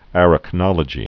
(ărək-nŏlə-gē)